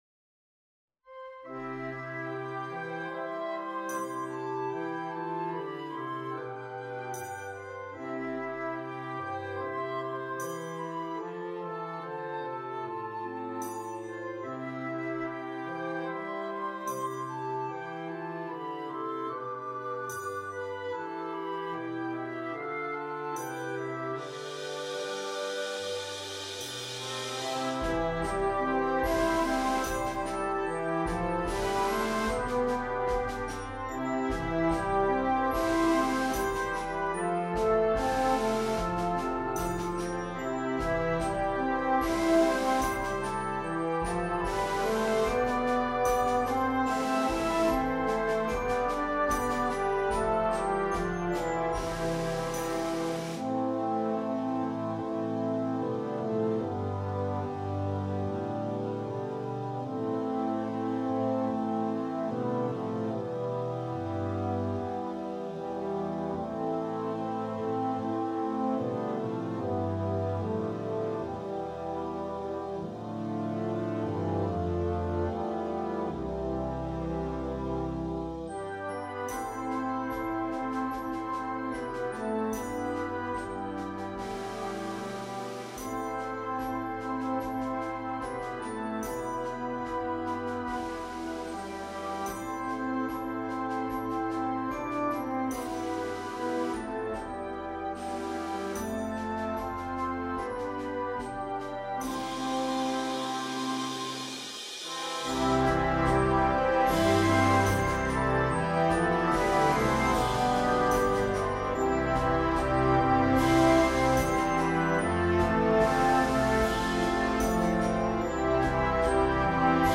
Embark on a reflective and melodic journey